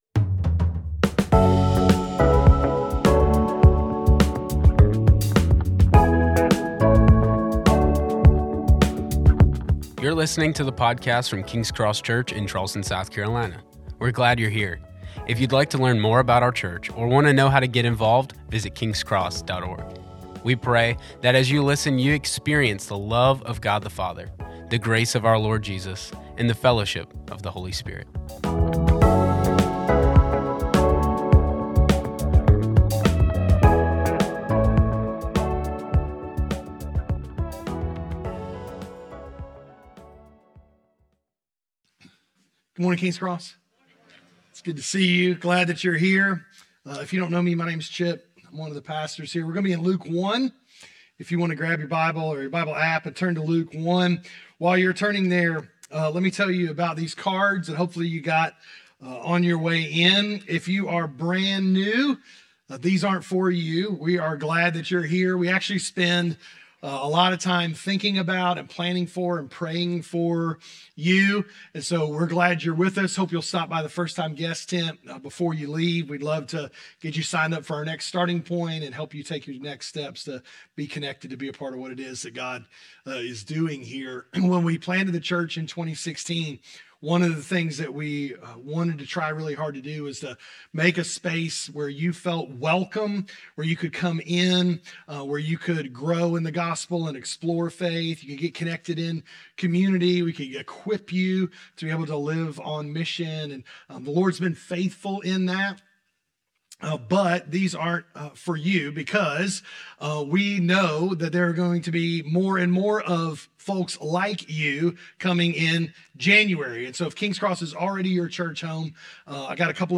A message from the series "In Those Days." Mary's Magnificat in Luke 1 reminds us who God is.